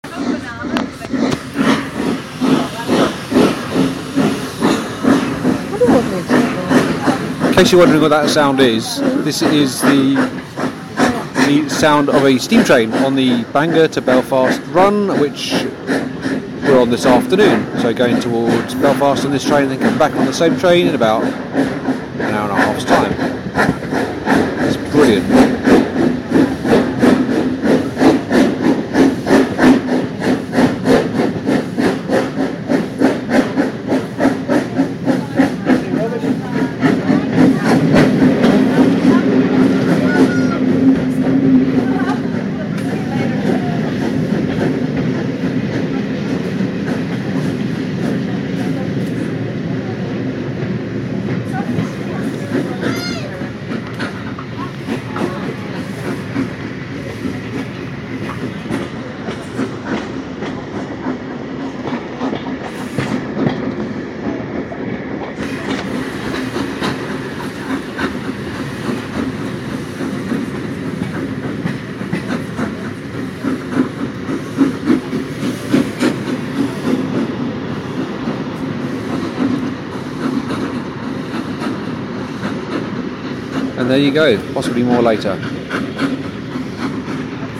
Steam train at Bangor
Steam train leaving Bangor station on the 150th anniversary of the railway